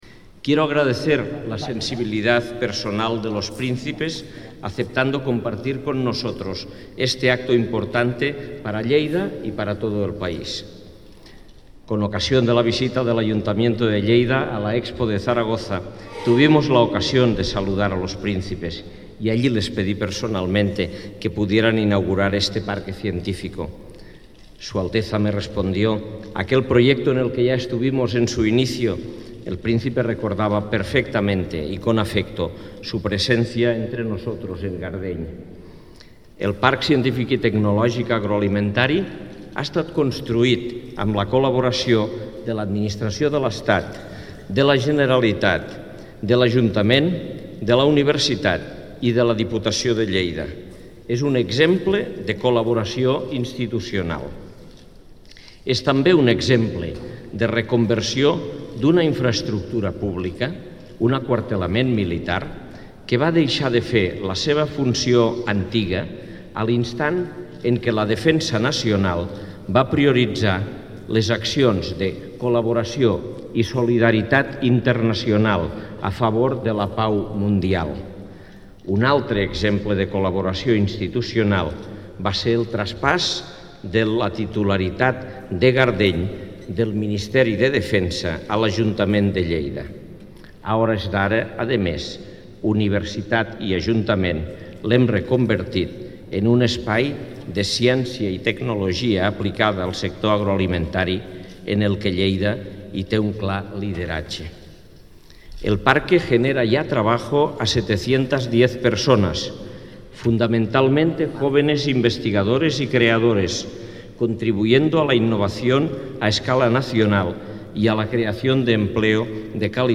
Intervenció de l'alcalde de Lleida, Àngel Ros (49.1 KB) Arxiu de so amb el discurs íntegre de l'alcalde Àngel Ros amb motiu de la inauguració oficial del Parc Científic de Lleida.